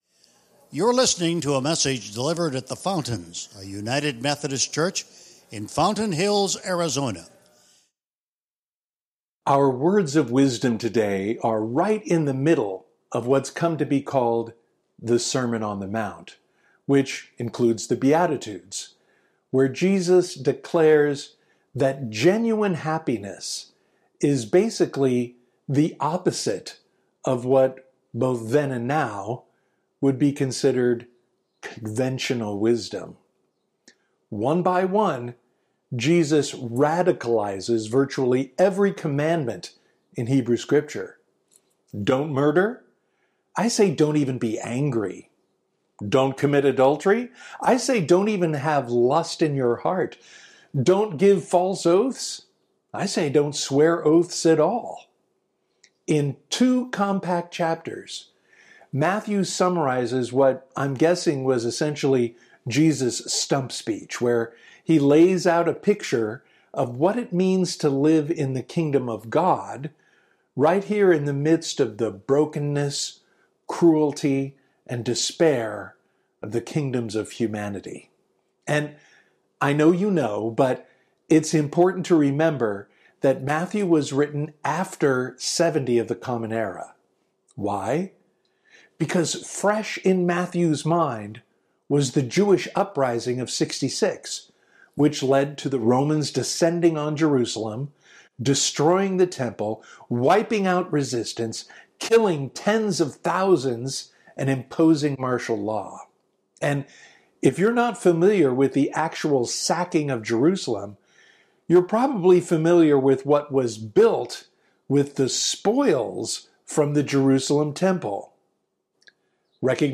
Sermon Archives | The Fountains, a United Methodist Church |